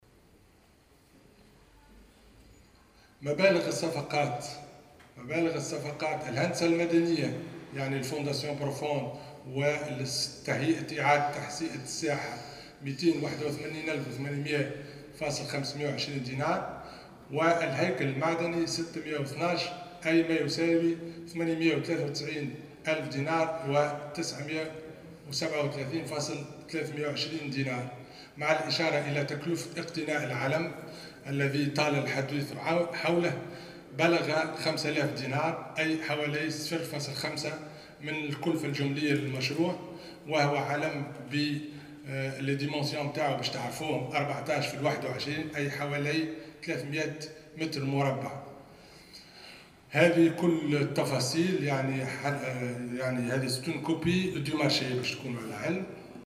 Le ministre a indiqué, lors de la plénière matinale de ce samedi à l'ARP, que le coût d'acquisition du drapeau est estimé à 5 mille dinars, soit 0.5% du coût total du projet.